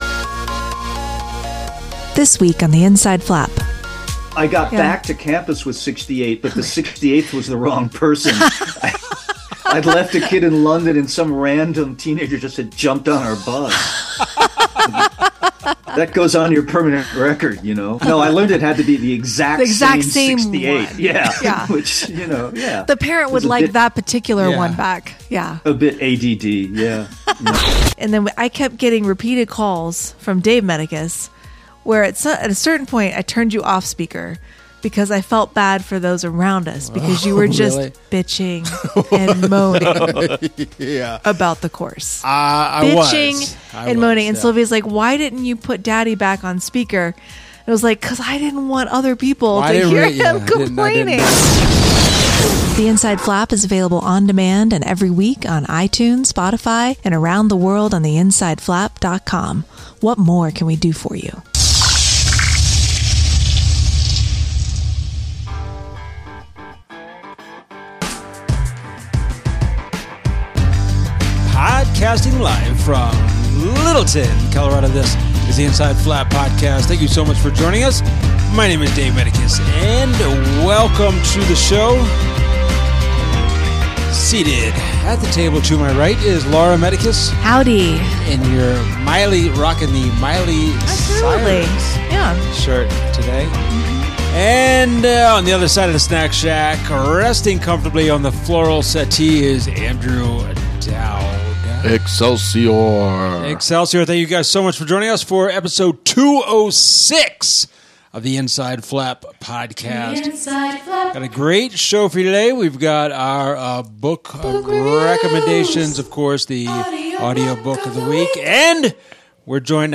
We’re joined by Don Winslow for an entertaining chat about his new novel City of Dreams, Greek myths, losing kids in England, controversial chowder takes, and the reason why one of his books has short chapters.